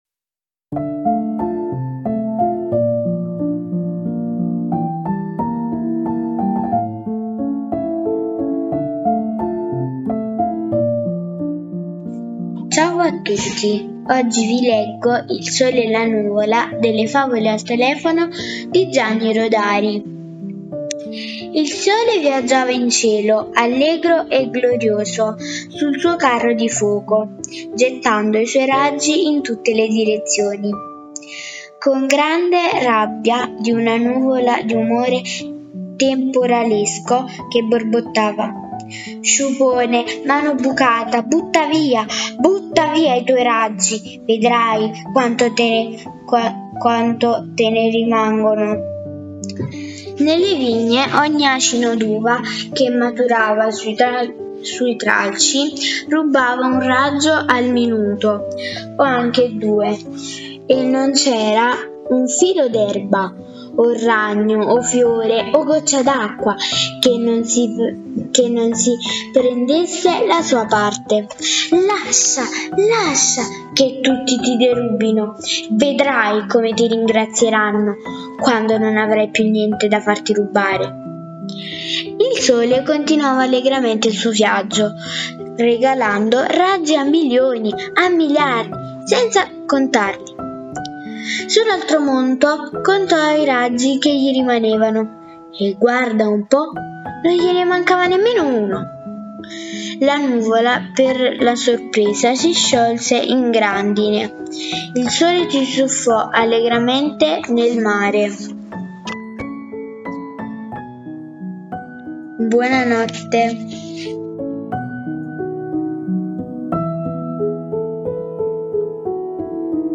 Oggi vi leggo “Il sole e la nuvola” tratta da libro “Favole al telefono” di Gianni Rodari